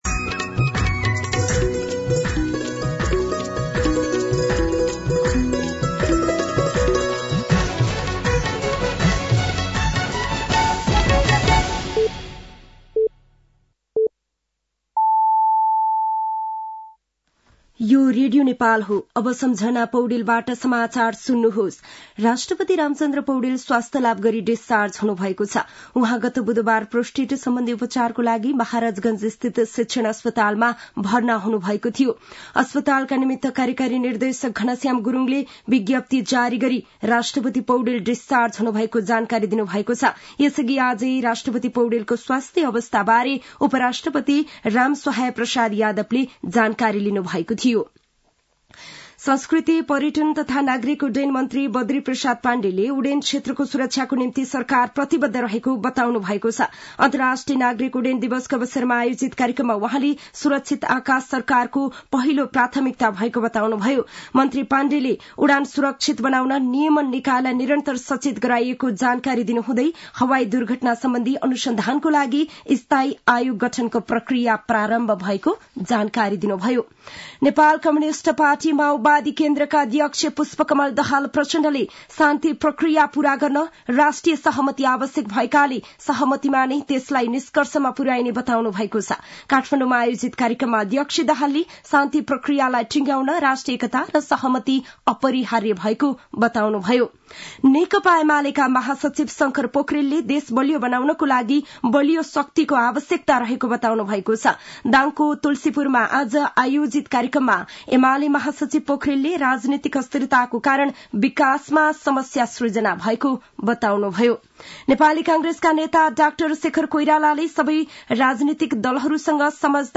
साँझ ५ बजेको नेपाली समाचार : २३ मंसिर , २०८१
5-PM-Nepali-News-8-22.mp3